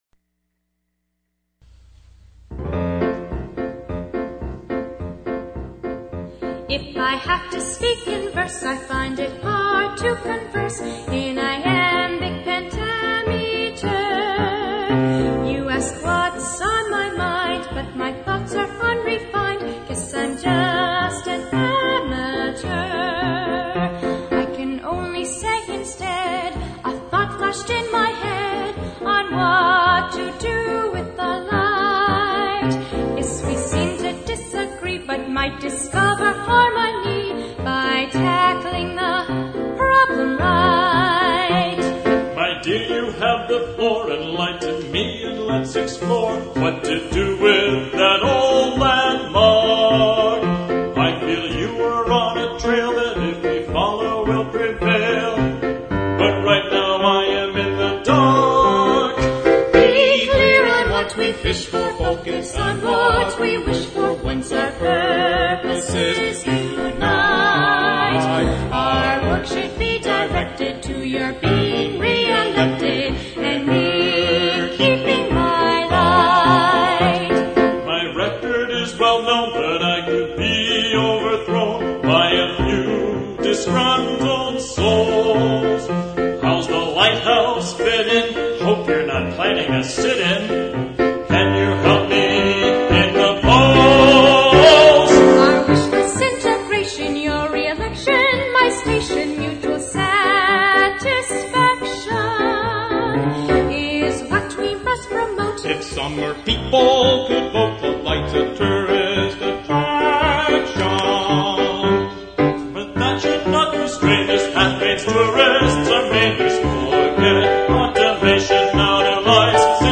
Here you can hear samples from both the original sample recording and from the premier perrformance at the Waldo Theater.